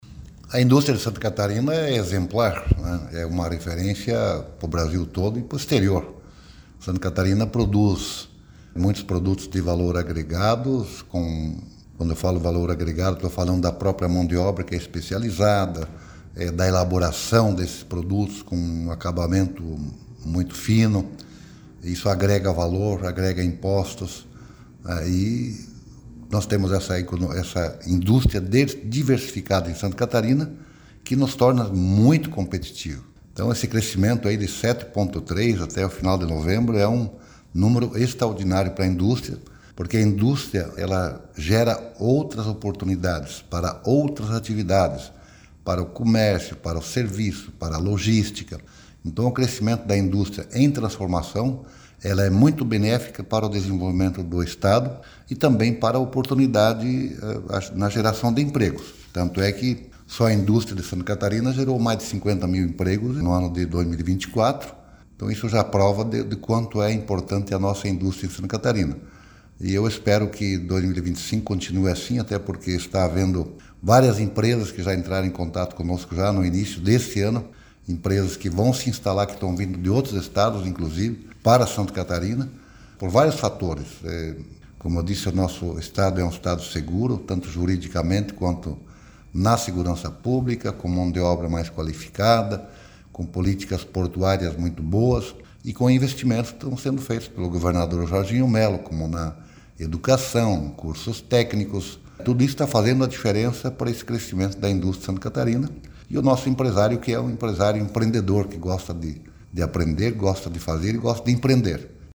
Para o secretário de Estado de Indústria, Comércio e Serviço, Silvio Dreveck, o setor industrial de Santa Catarina passa por um momento muito positivo com a produção crescendo e os investimentos privados também: